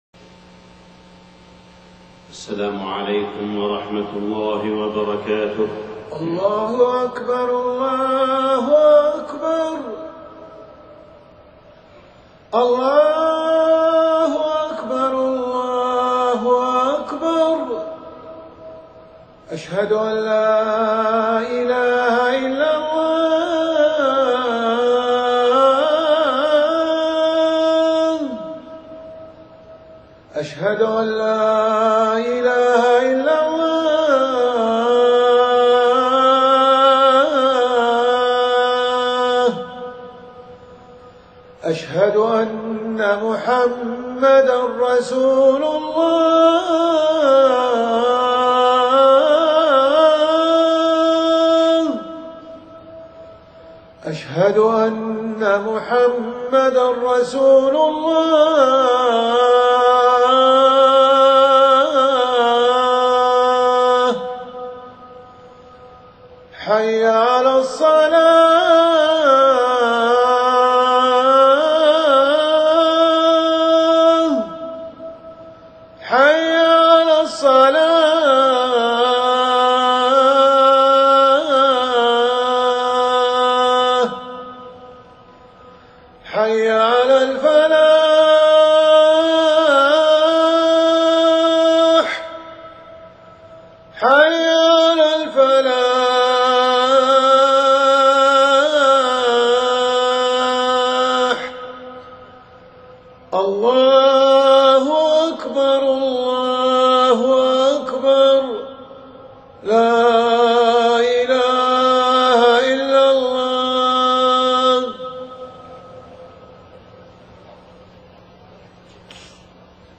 خطبة الجمعة 19 محرم 1430هـ > خطب الحرم المكي عام 1430 🕋 > خطب الحرم المكي 🕋 > المزيد - تلاوات الحرمين